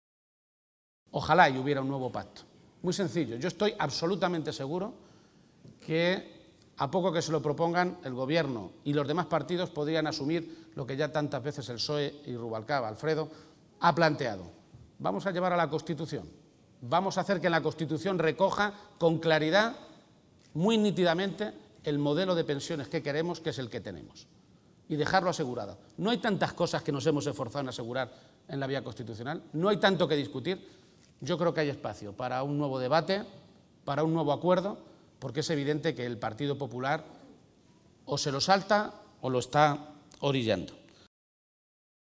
García-Page se pronunciaba de esta manera esta mañana, en Toledo, durante el primer gran acto a nivel nacional que el PSOE organiza en defensa del sistema de pensiones y en el que ha estado acompañado por el secretario general del PSOE, Alfredo Pérez Rubalcaba.